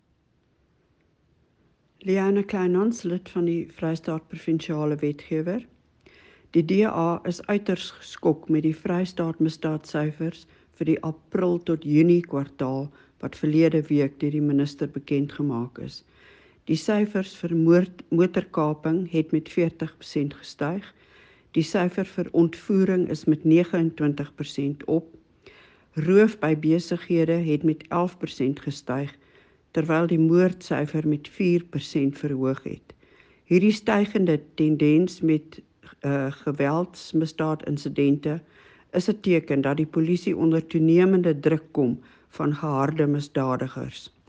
Afrikaans soundbites by Leona Kleynhans MPL